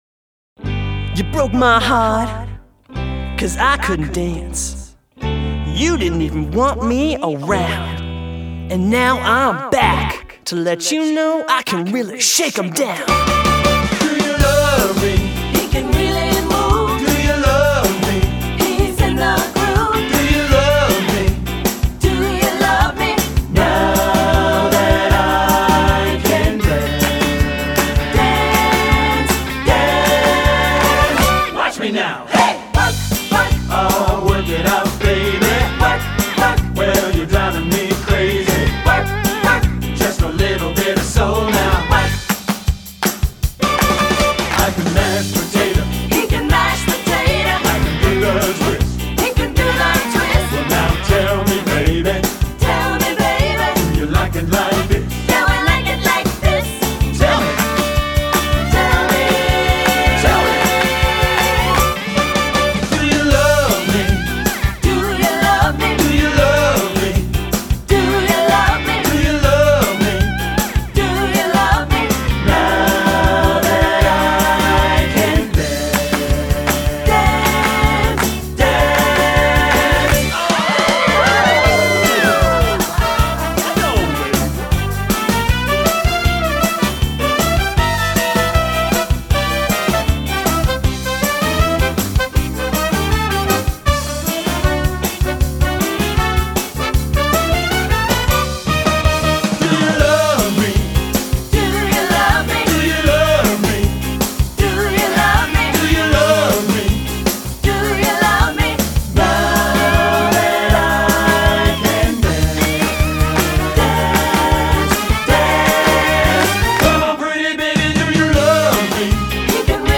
Répertoire pour Baryton/basse - Basses